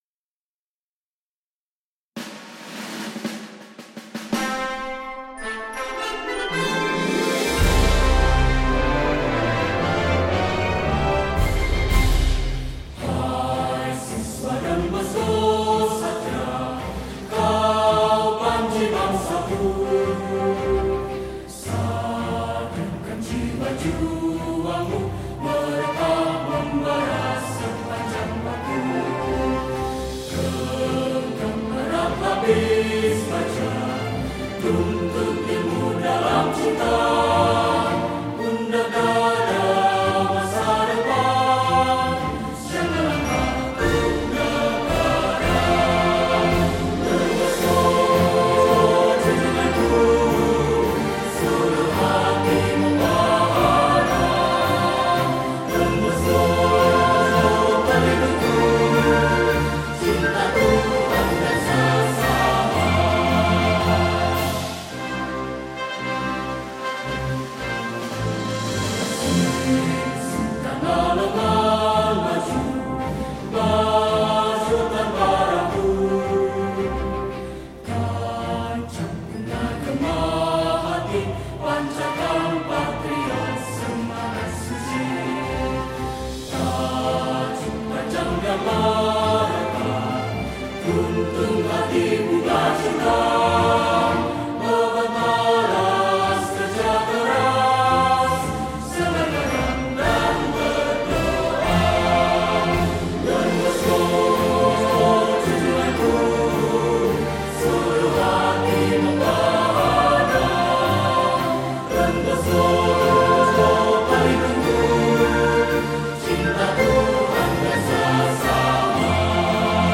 1= F 4/4 Marcia